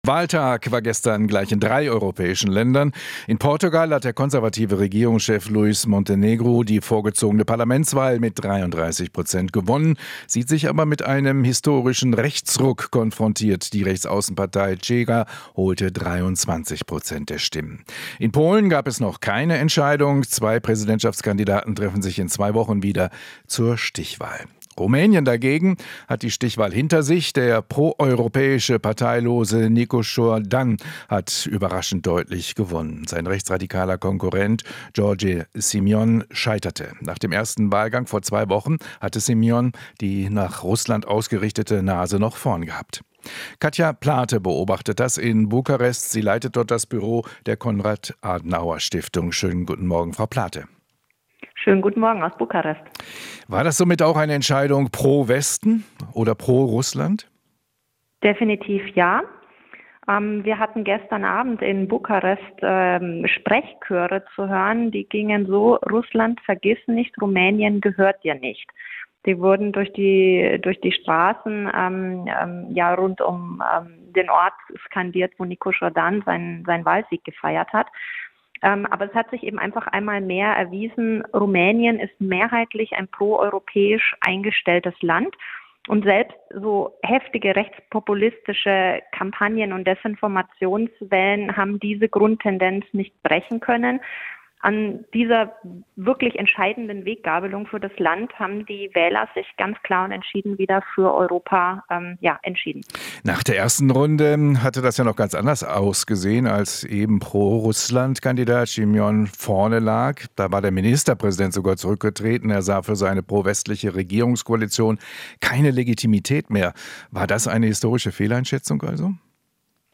Interview - Liberaler Dan gewinnt Präsidentschaftswahl in Rumänien